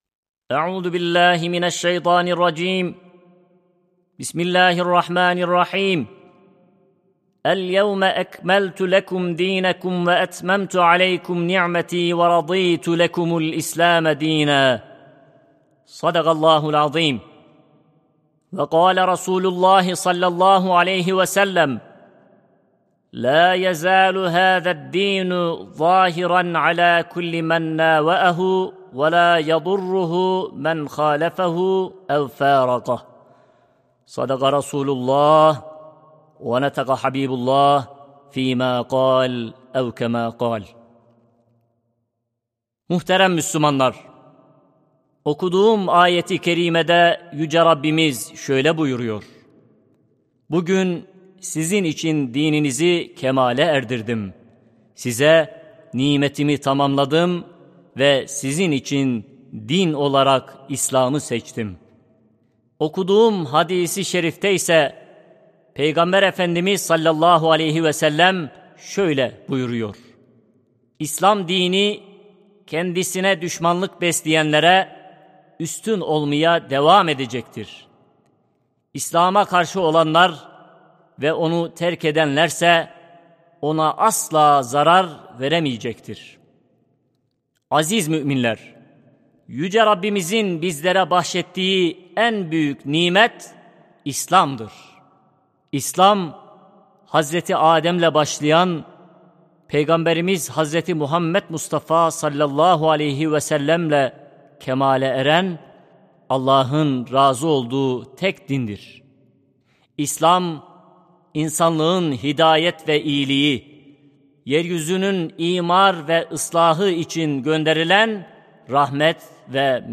Sesli Hutbe (İslam, Varlık Sebebimizdir).mp3